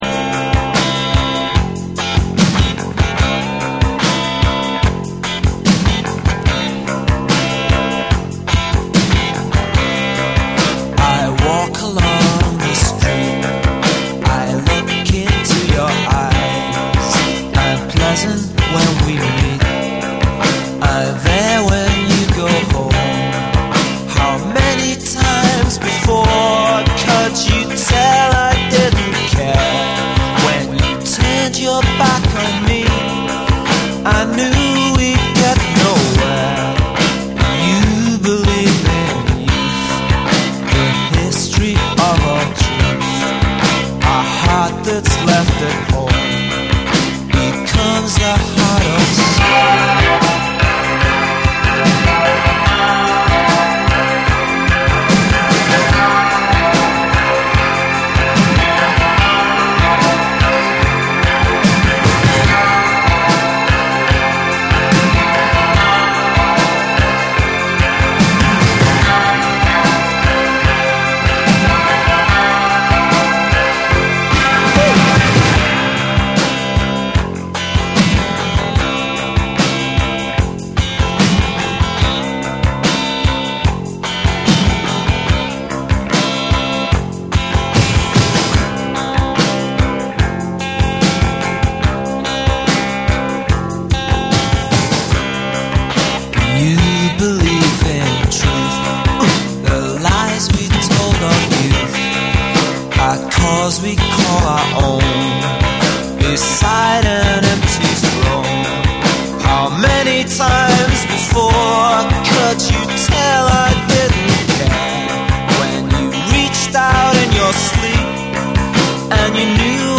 メランコリックなムードが際立つ